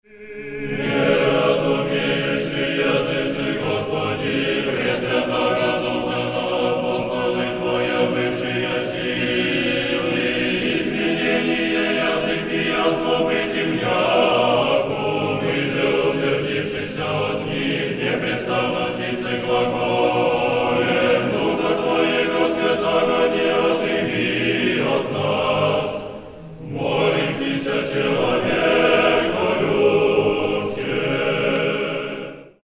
Песнопения праздника Троицы
Стихиры на стиховне. Хор Московского Сретенского монастыря
stihira_na_stihovne-386bd7.mp3